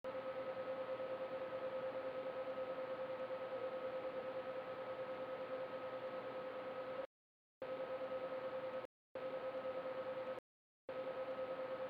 The "pop" is subtle but quite evident.